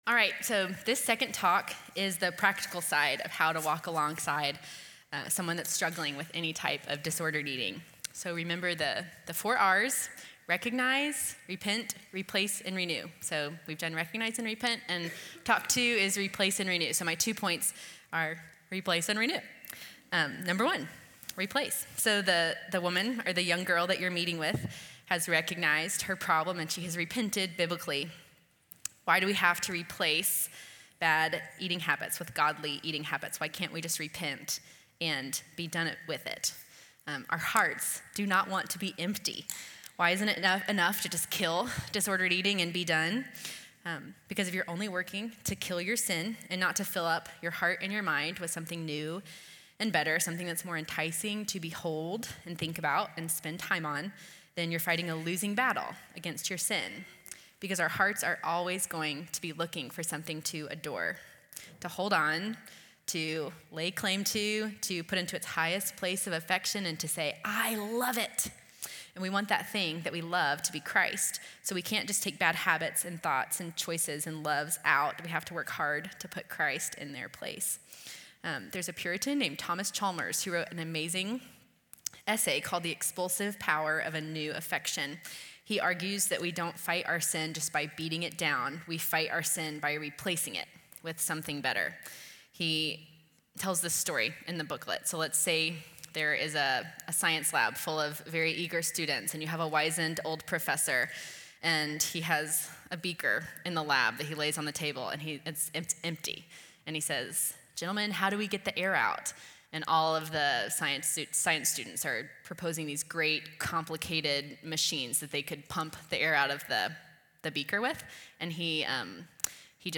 Audio recorded at Feed My Sheep for Pastors Wives Conference 2024.